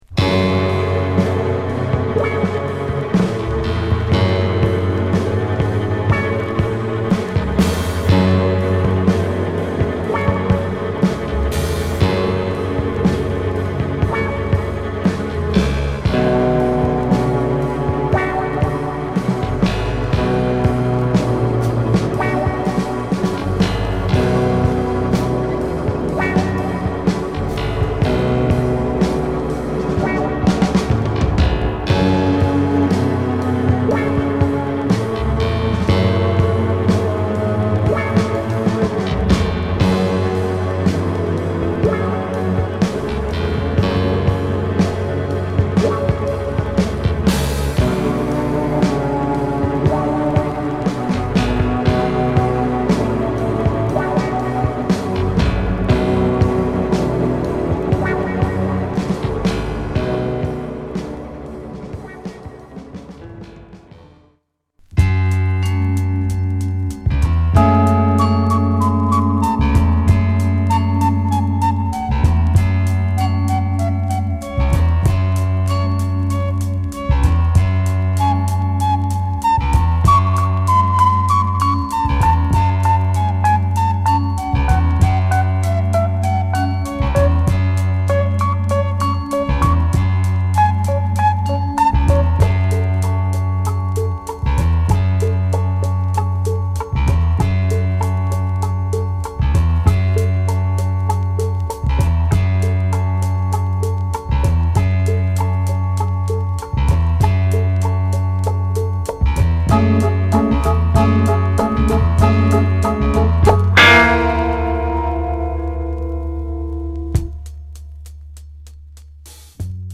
なかなかドープな映画ですが、サウンドの方も負けずに鬼ドープ！
サントラらしいシンセの効果音〜不穏な空気感漂いまくるロウなビートまで